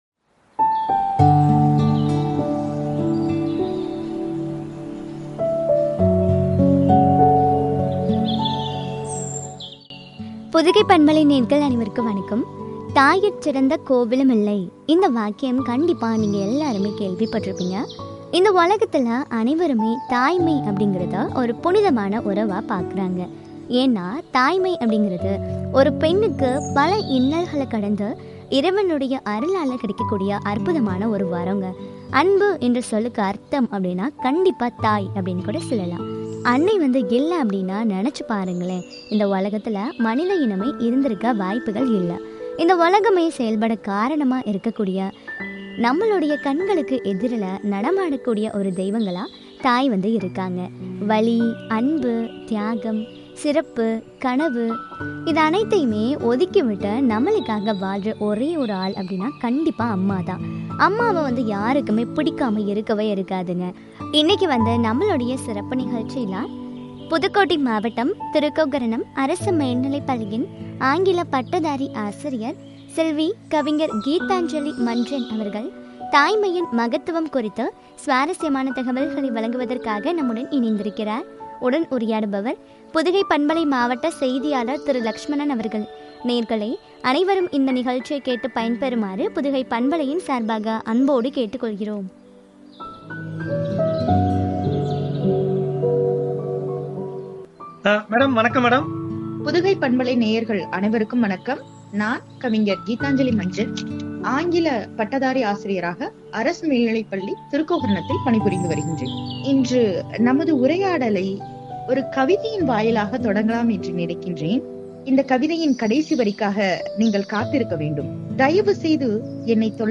வழங்கிய உரையாடல்.